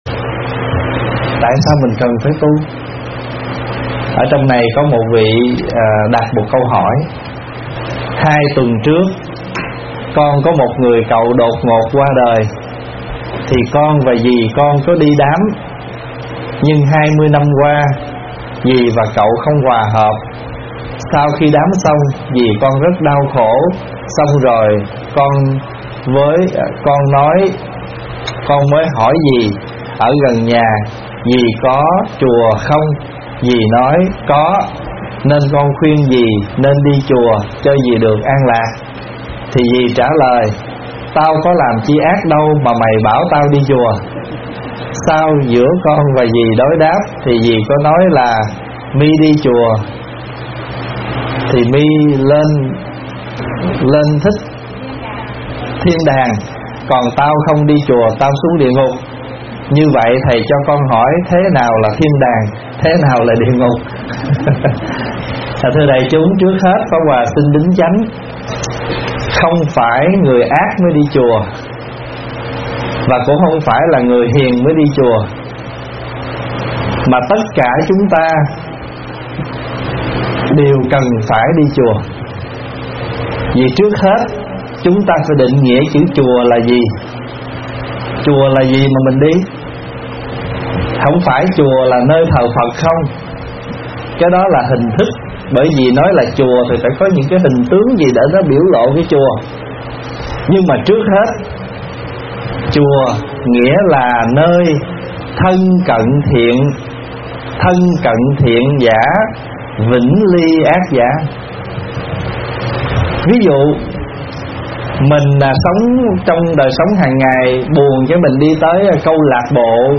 Nghe Mp3 thuyết pháp Tại sao phải đi Chùa?